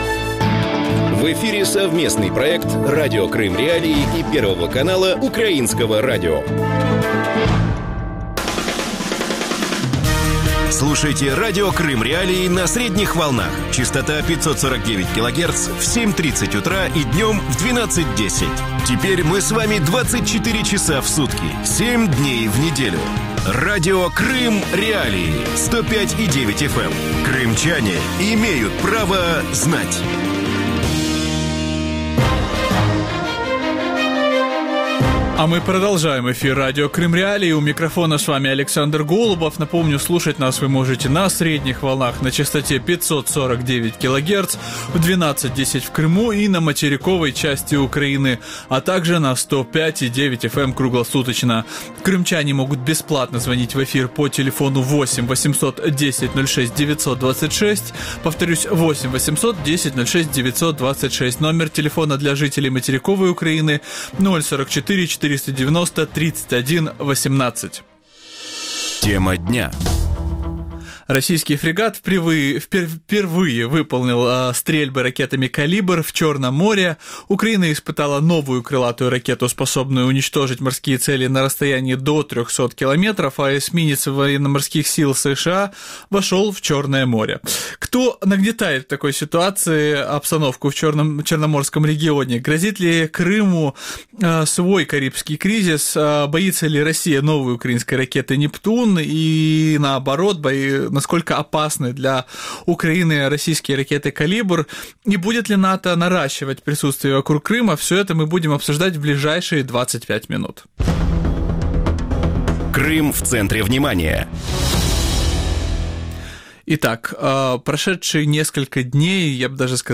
в эфире ток-шоу Радио Крым.Реалии